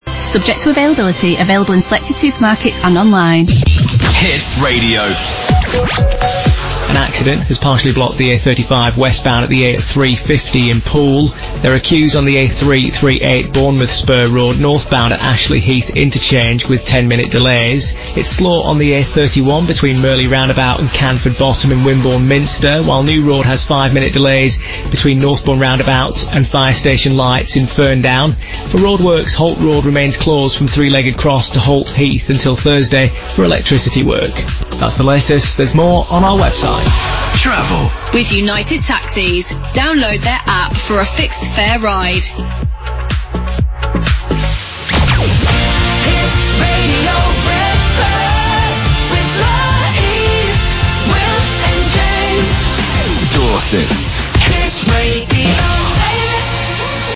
Most of the traffic bulletins on Greatest Hits Radio and Hits Radio are now voiced by computers rather than people.
Bulletins are generated through INRIX’s traffic intelligence and automation systems, with delivery informed by the tone and style of our experienced broadcast journalists to ensure continuity and familiarity for listeners.
Here are some examples of the bulletins.